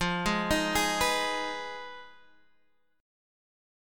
Fm7b5 chord